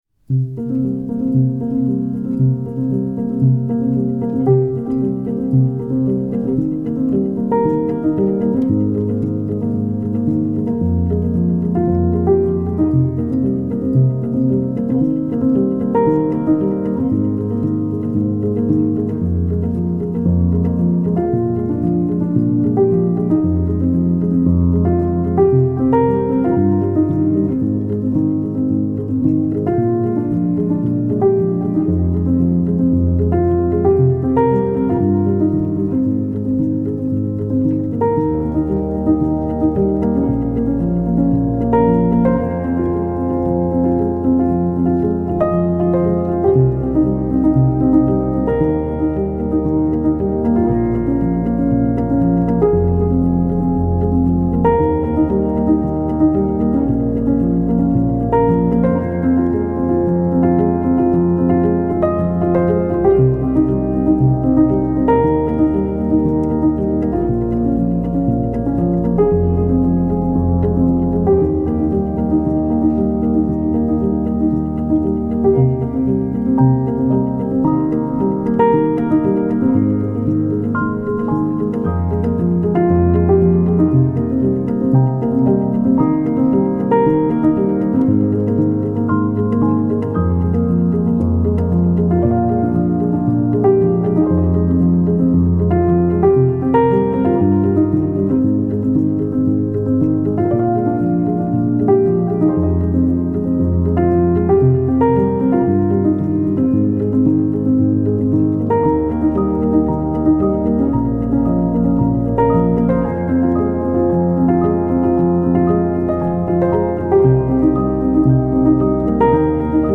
موسیقی کنار تو
پیانو
عمیق و تامل برانگیز , مدرن کلاسیک